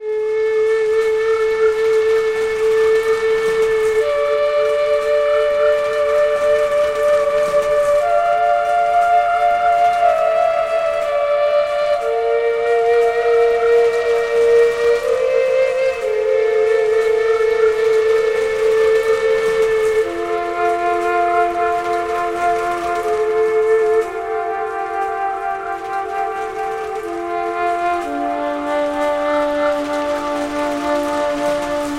标签： 60 bpm Ambient Loops Pad Loops 2.69 MB wav Key : E
声道立体声